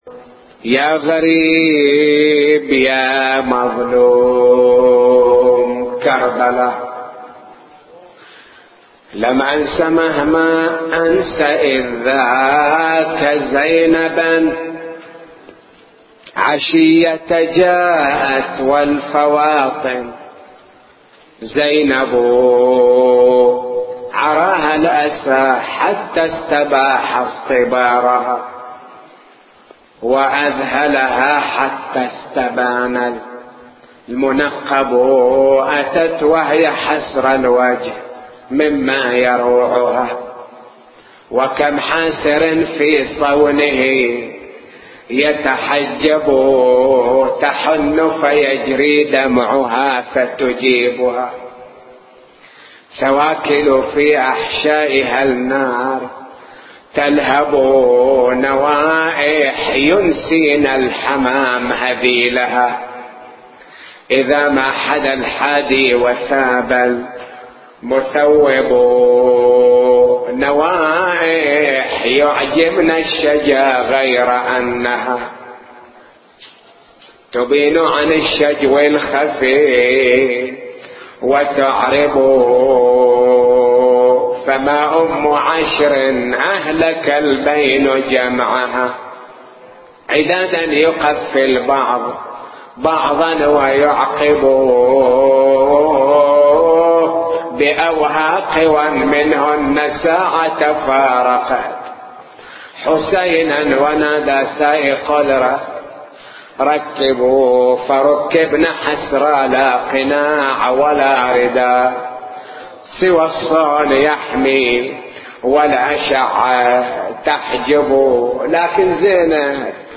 نواعي وأبيات حسينية – 2